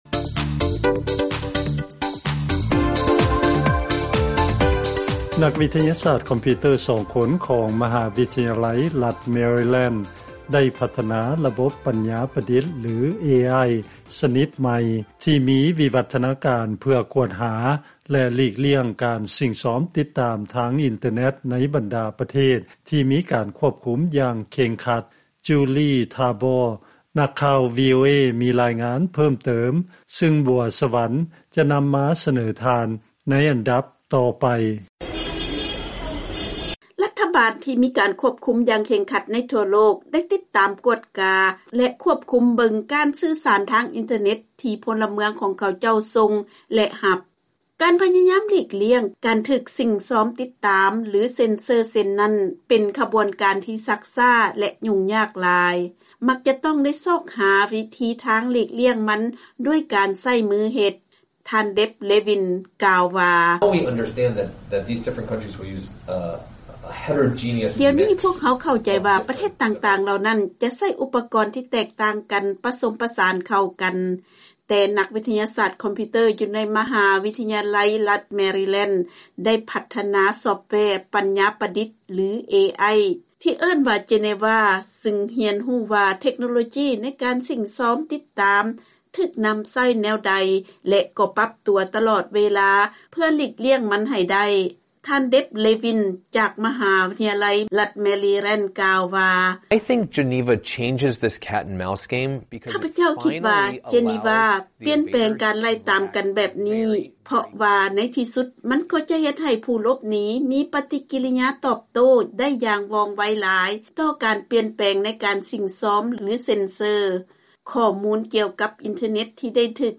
ເຊີນຟັງລາຍງານກ່ຽວກັບການໃຊ້ເທັກໂນໂລຈີເພື່ອຕ້ານກັບການສິ້ງຊອມຄວບຄຸມການໃຊ້ອິນເຕີແນັດ